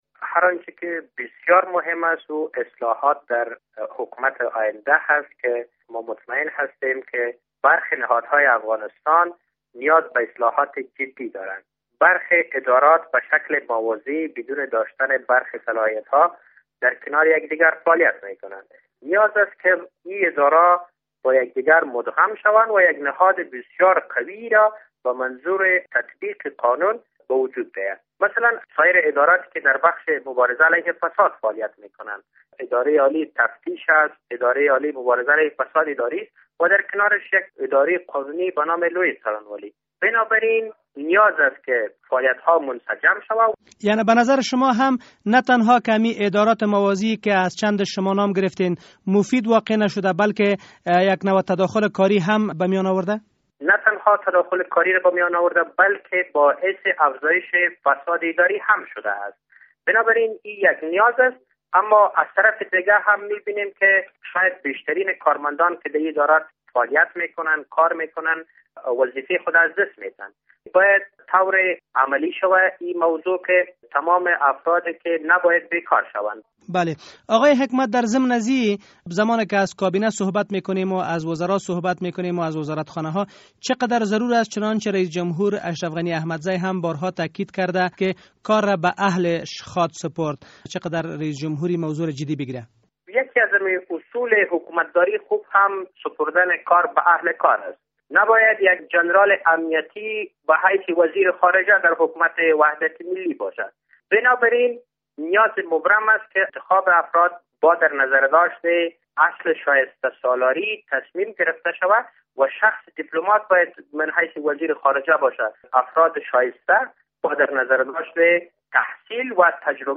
مصاحبه: مدغم و انحلال ادارات اضافی تا چه حد ضروری است